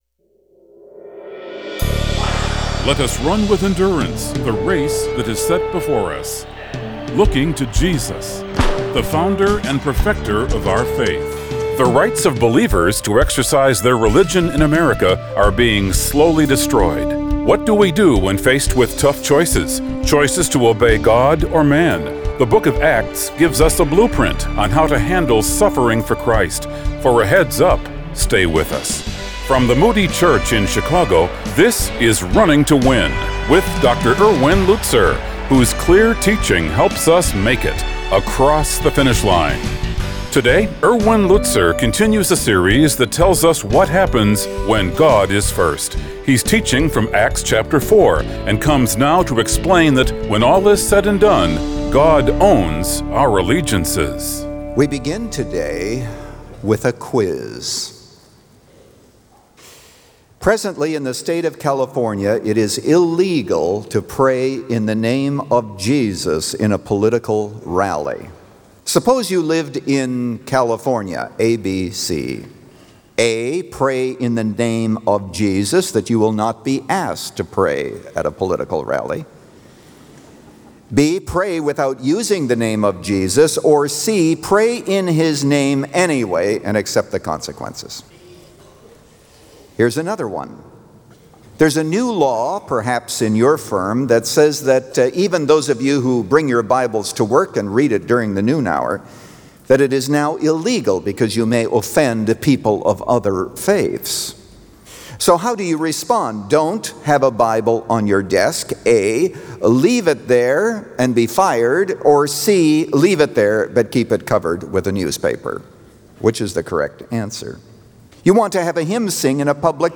He Owns Our Allegiances – Part 1 of 3 | Radio Programs | Running to Win - 15 Minutes | Moody Church Media